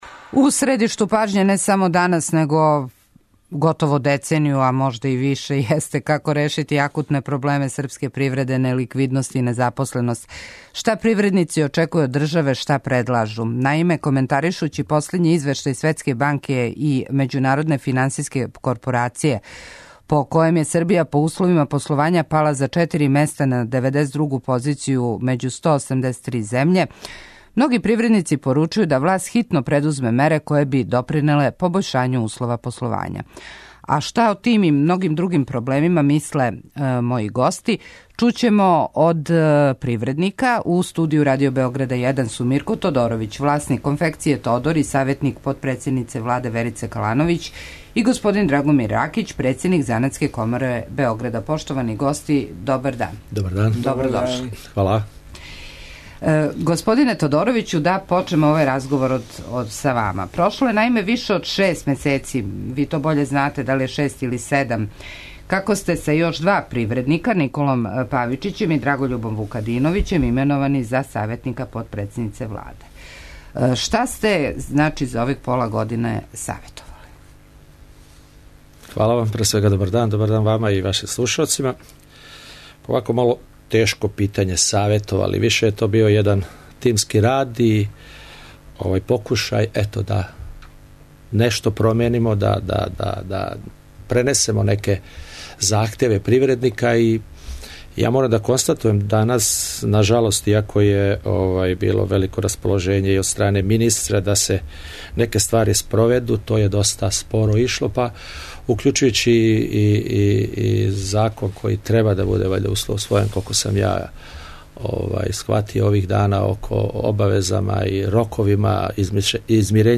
Гости у студију су - привредници
интервју